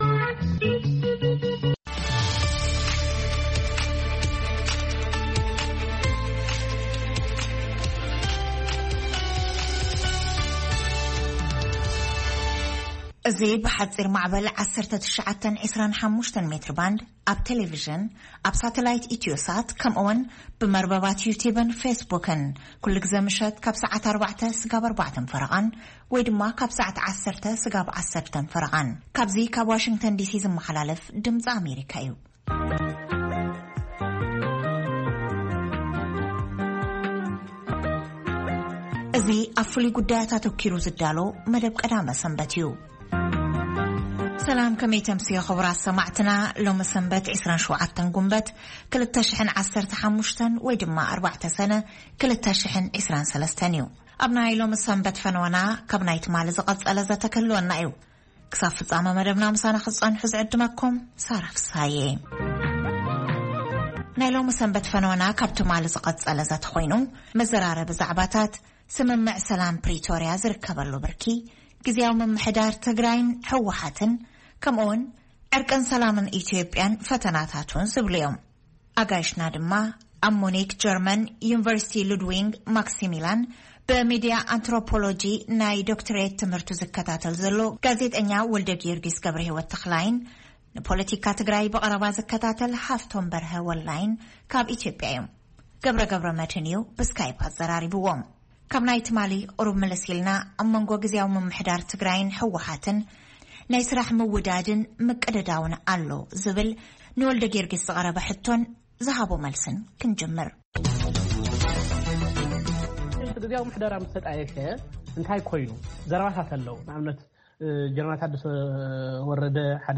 ድምጺ ኣሜሪካ ፈነወ ቛንቛ ትግርኛ ካብ ሶኑይ ክሳብ ሰንበት ይፍነው። ፈነወ ቛንቛ ትግርኛ ካብ ሶኑይ ክሳብ ዓርቢ ብዕለታዊ ዜና ይጅምር፥ እዋናዊ ጉዳያትን ሰሙናዊ መደባት'ውን የጠቓልል ።ቀዳምን ሰንበትን ኣብቲ ሰሙን ዝተፈነው መደባት ብምድጋም ፈነወ ቛንቛ ትግርኛ ይኻየድ።